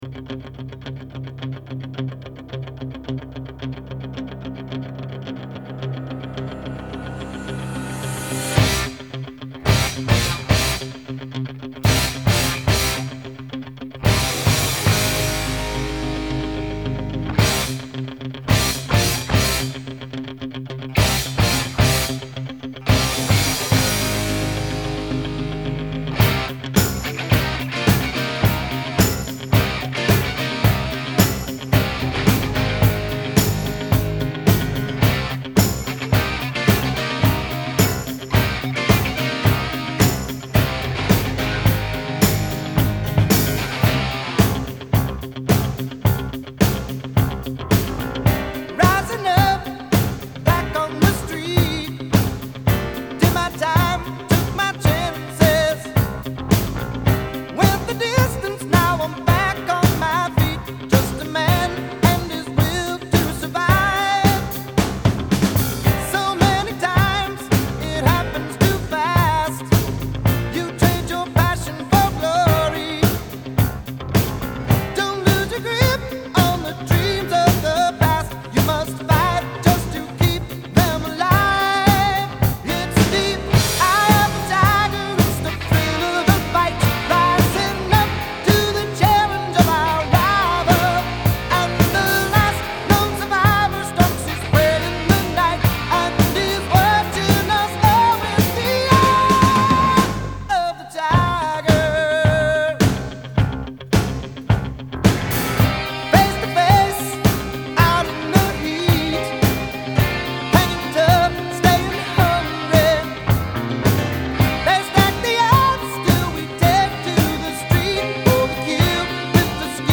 Rock 80er